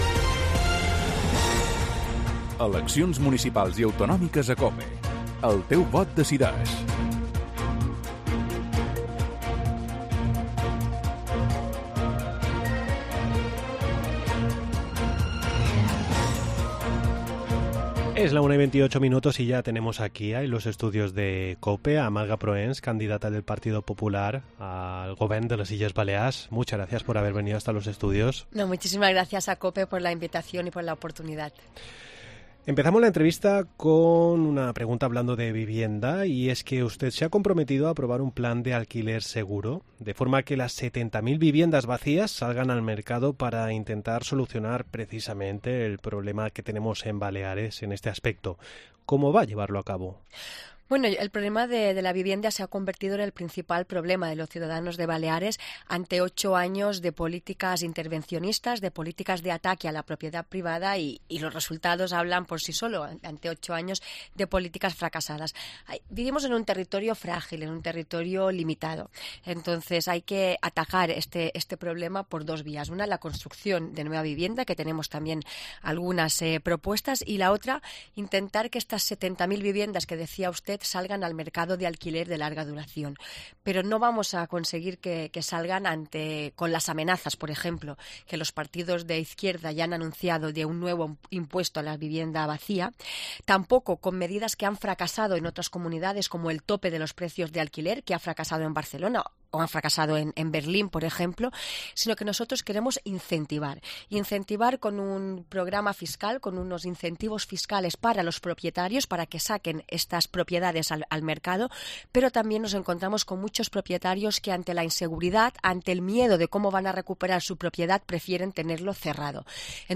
AUDIO: Continuamos con la ronda de entrevistas de cara a las próximas elecciones. Hoy visita los estudios de Cope Marga Prohens, candidata del...